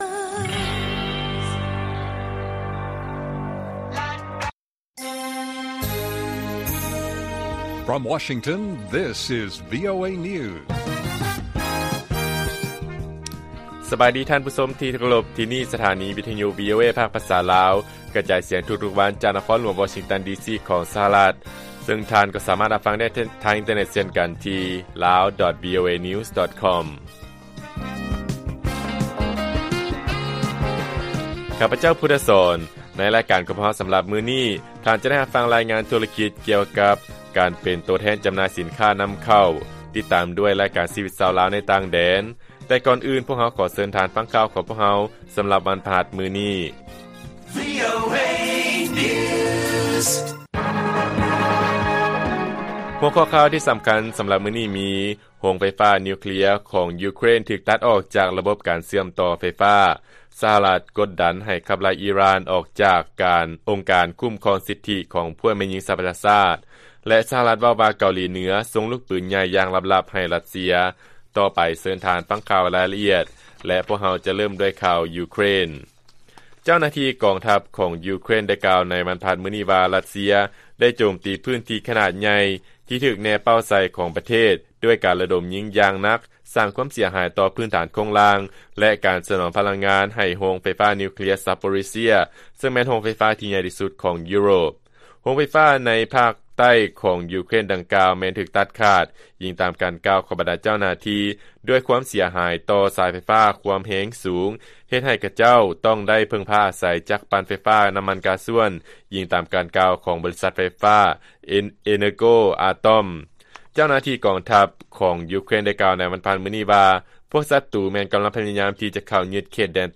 ລາຍການກະຈາຍສຽງຂອງວີໂອເອ ລາວ: ໂຮງໄຟຟ້ານິວເຄລຍ ຂອງ ຢູເຄຣນ ຖືກຕັດອອກຈາກລະບົບການເຊື່ອມຕໍ່ໄຟຟ້າ